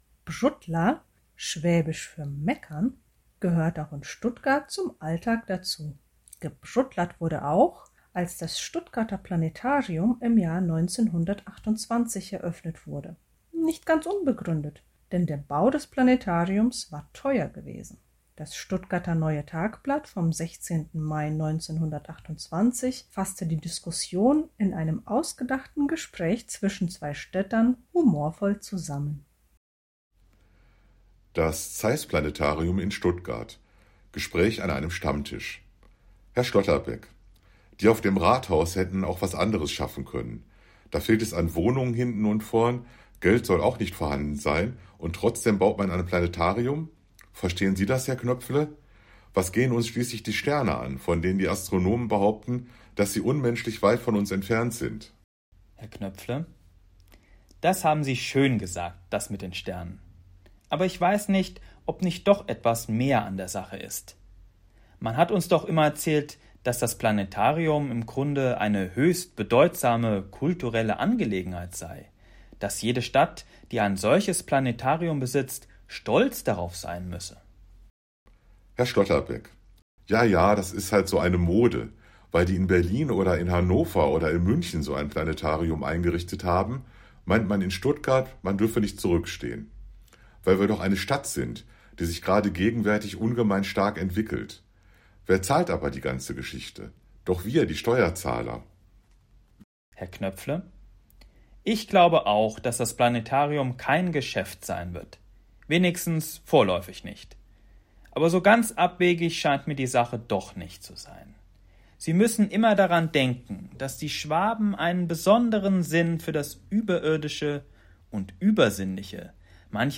Hör mal hin! Ein Stuttgarter Stammtischgespräch über die Eröffnung des Planetariums im Jahre 1928